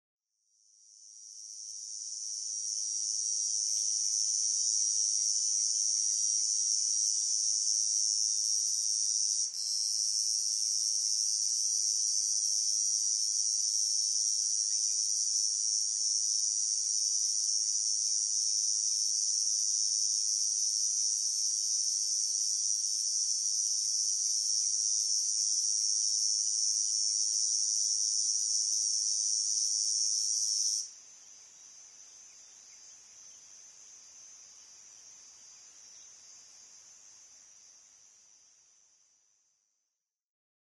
コエゾゼミ　Tibicen bihamatusセミ科
日光市稲荷川中流　alt=1170m  HiFi --------------
Mic.: audio-technica AT825
他の自然音：　 ウグイス